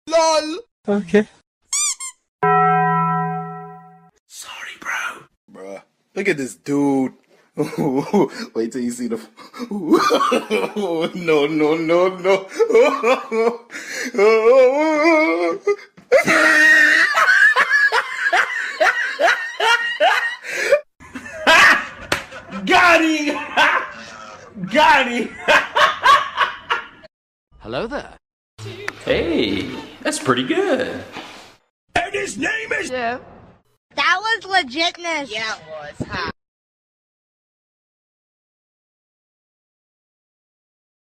Download These 10 Sound Effects sound effects free download